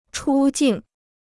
出境 (chū jìng) 무료 중국어 사전